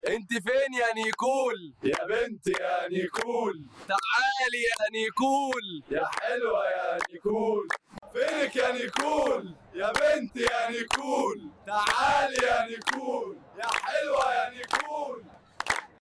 🚨 مظاهرة غير عادية في sound effects free download
أجواء ضحك وحب وجمال